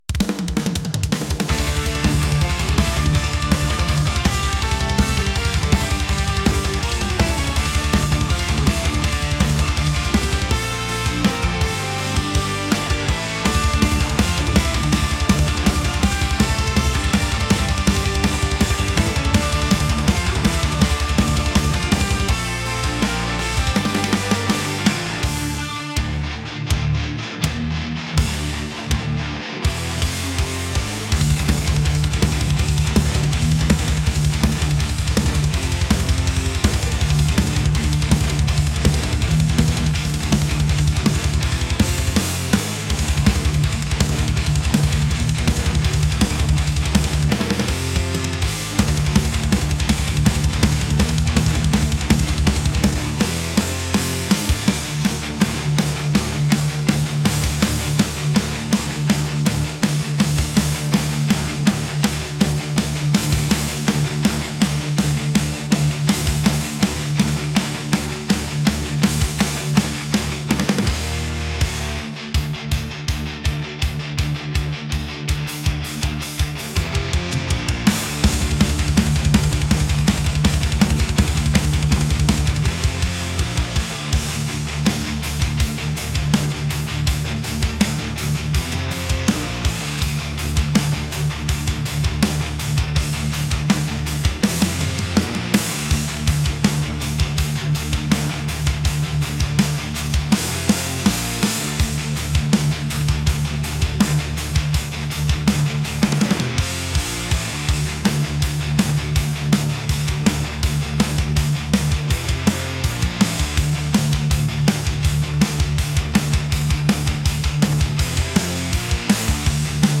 metal | aggressive